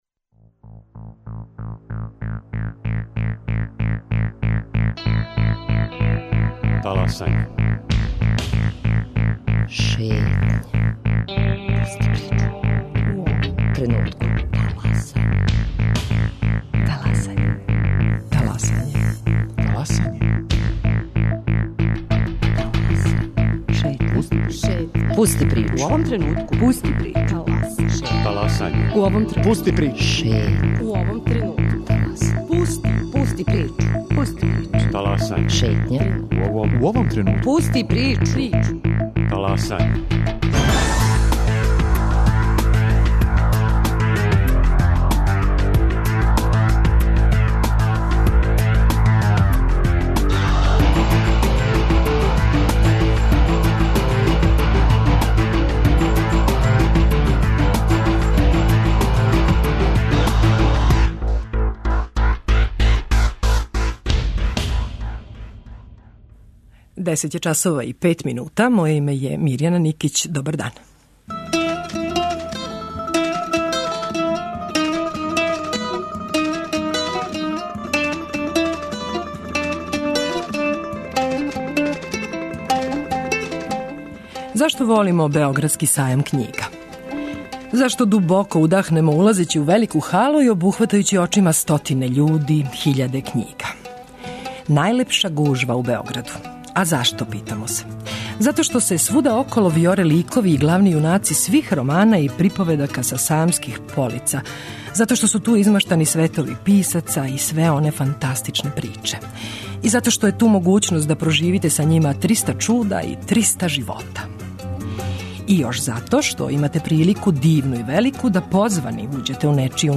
На данашњи дан 1961. године саопштено је да је добитник Нобелове награде за књижевност Иво Андрић. Током сајамског сата, шетаћемо Андрићевим делом, прелиставати његово штиво, ослушнути део његовог говора из Стокхолма са доделе Нобелове награде.